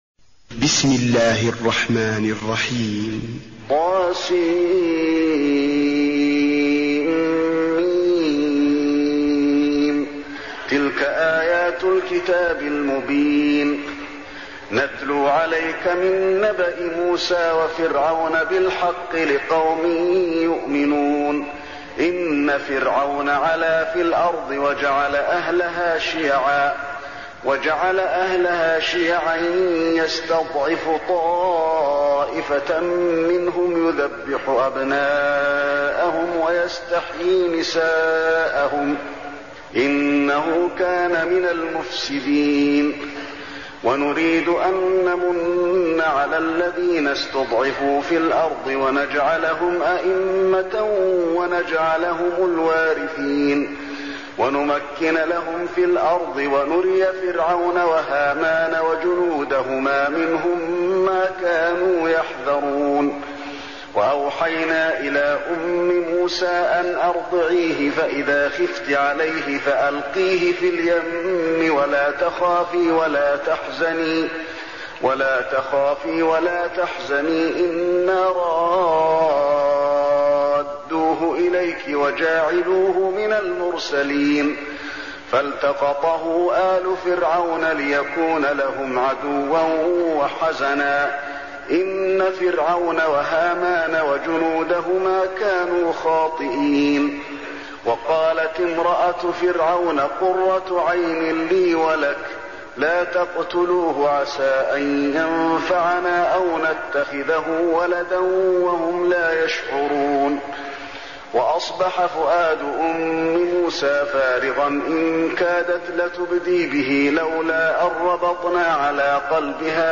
المكان: المسجد النبوي القصص